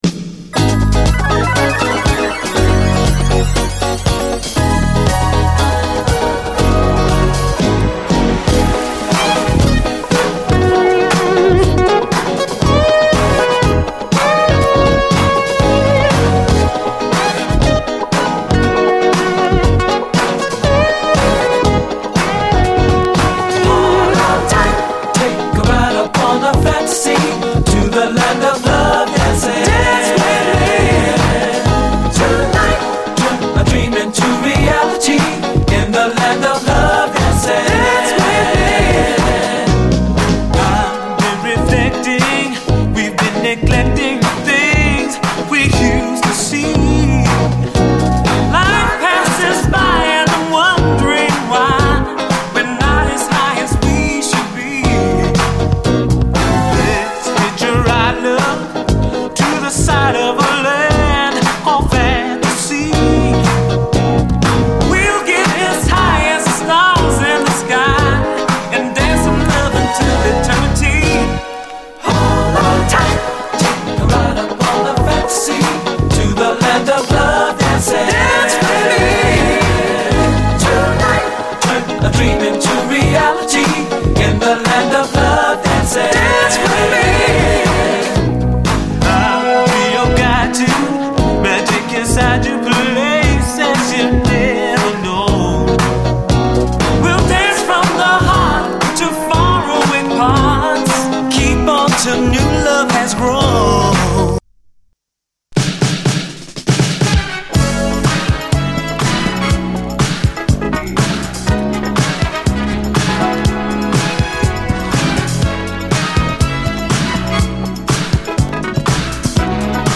SOUL, 70's～ SOUL, DISCO
試聴はこの盤からの録音です。